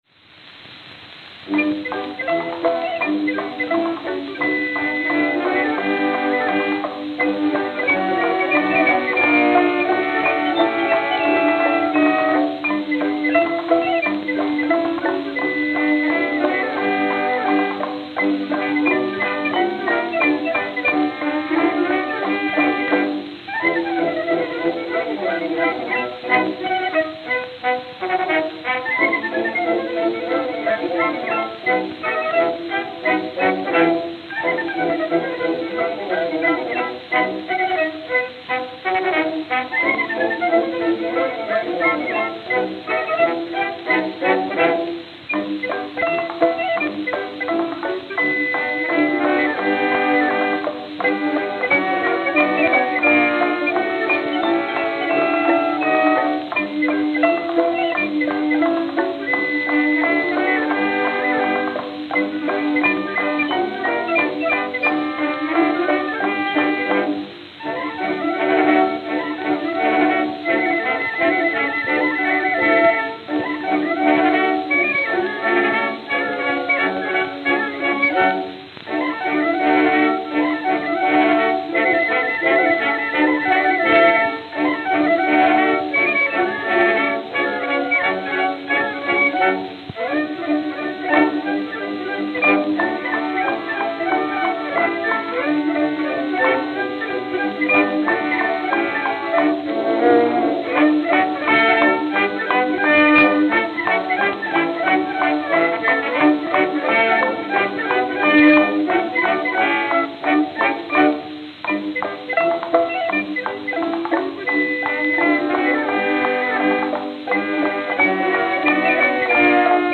Camden, New Jersey (?) Camden, New Jersey (?)